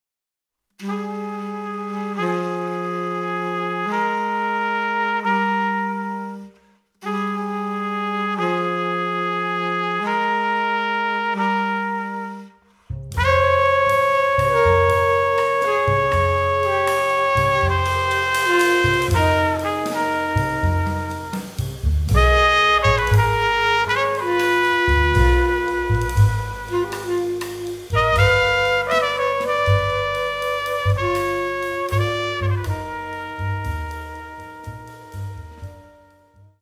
sax
trumpet
bass
drums